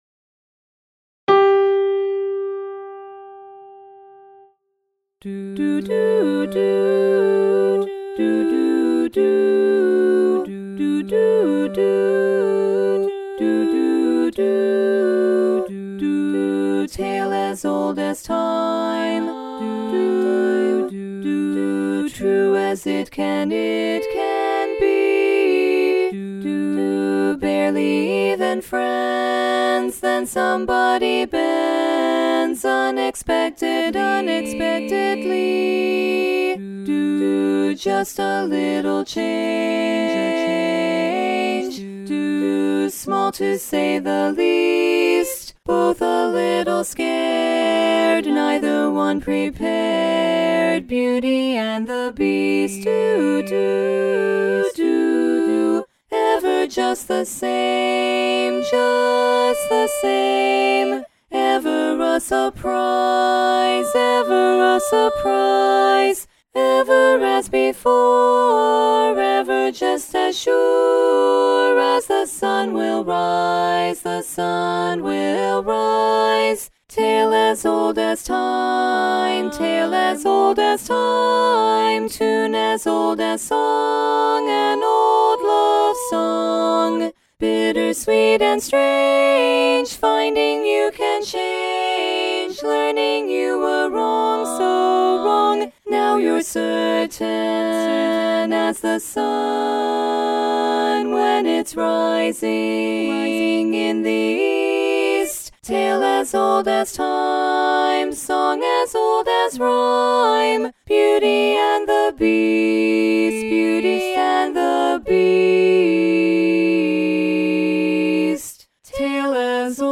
Bari